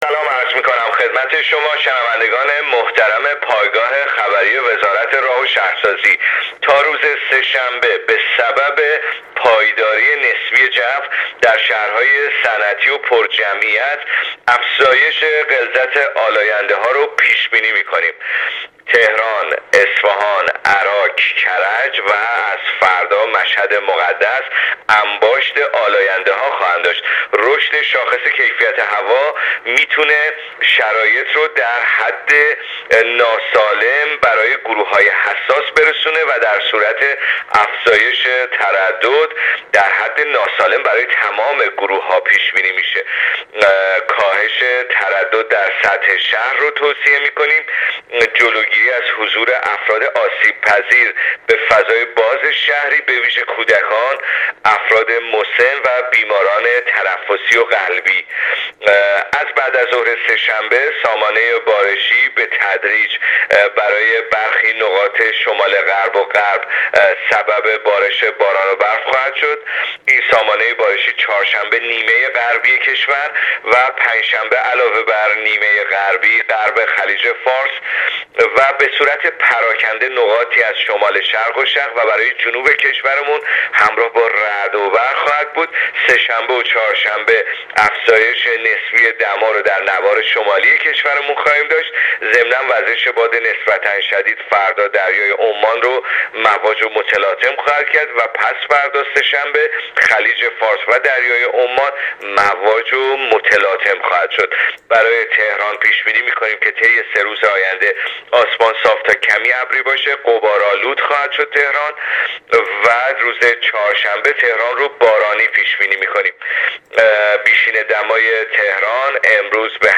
گزارش رادیو اینترنتی از آخرین وضعیت آب‌‌و‌‌‌هوای ۲۳ آذرماه